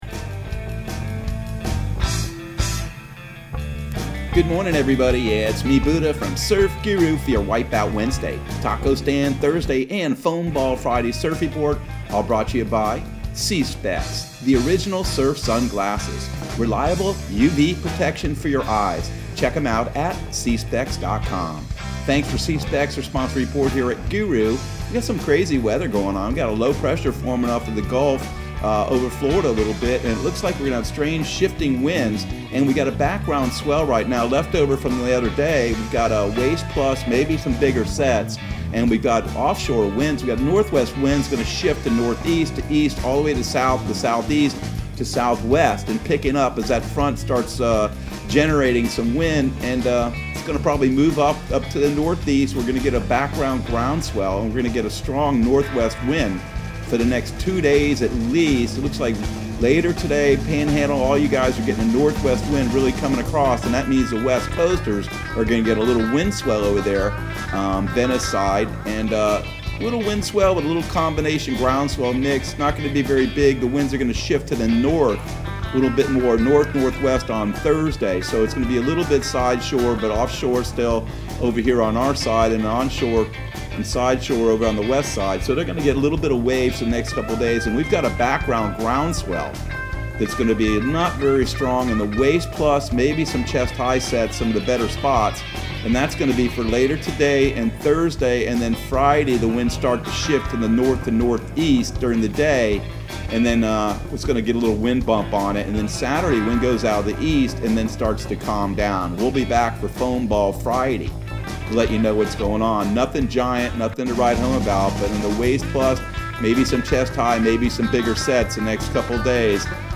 Surf Guru Surf Report and Forecast 12/16/2020 Audio surf report and surf forecast on December 16 for Central Florida and the Southeast.